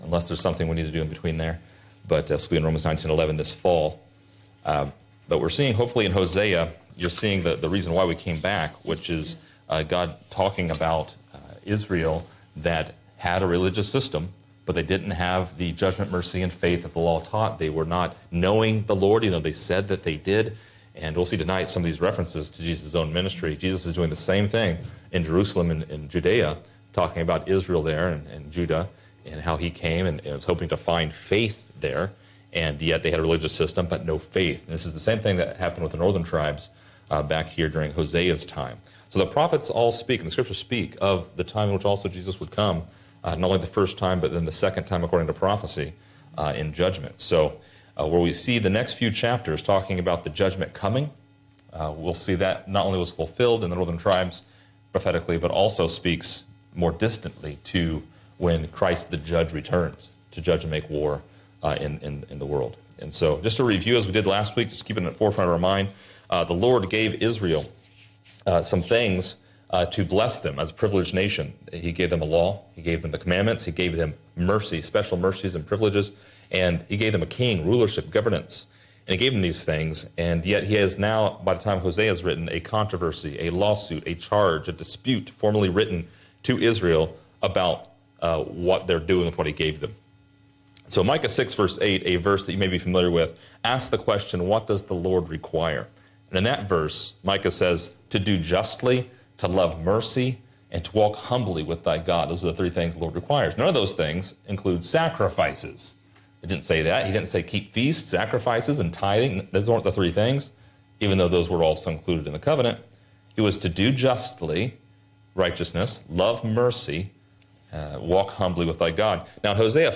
Description: This lesson is part 11 in a verse by verse study through Hosea titled: No Peace – No Mercy.